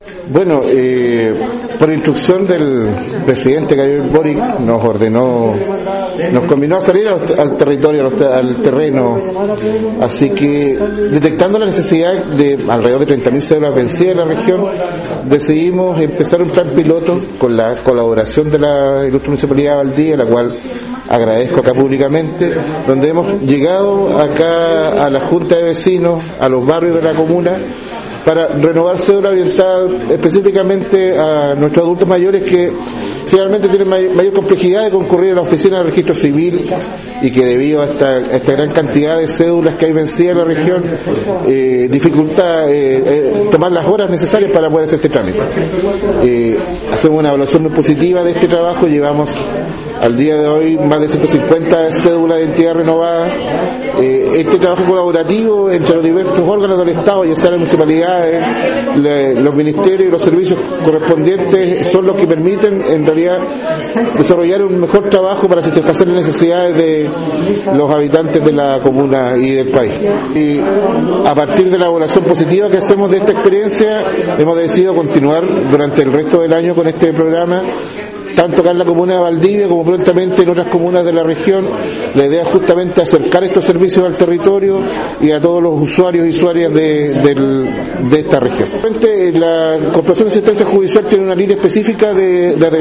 cuna-seremi-de-Justicia-y-DDHH-Esteban-Matus1.mp3